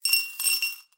杯子里的硬币 " 杯子里的硬币 10
描述：冰岛克朗被丢入一杯。
标签： 变化 季度 玻璃 货币 硬币 硬币 硬币
声道立体声